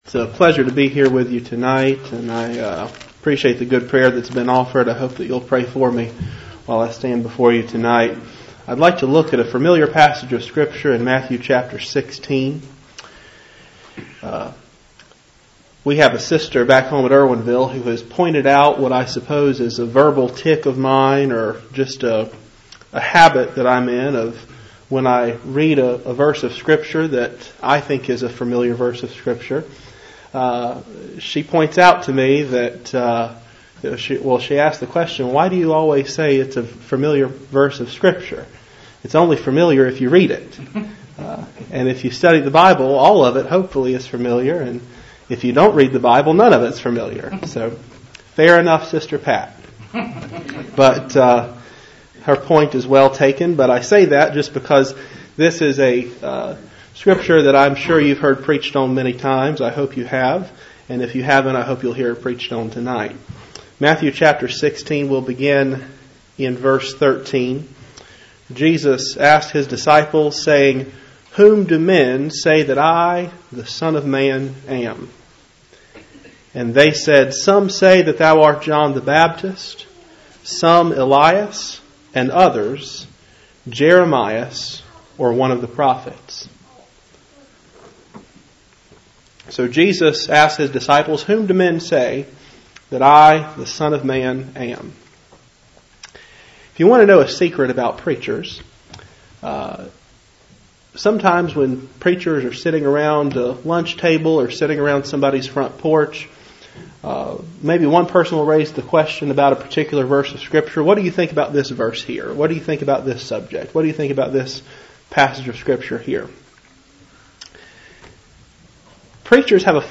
Matthew 16:13-18 Service Type: Cool Springs PBC Sunday Evening %todo_render% « Absalom’s Rise